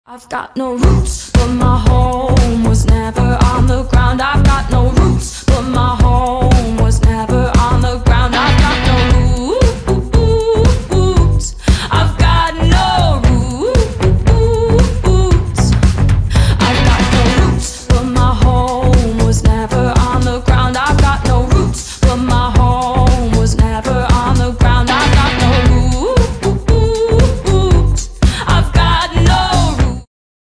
• Качество: 320, Stereo
поп
ритмичные
веселые
озорные
vocal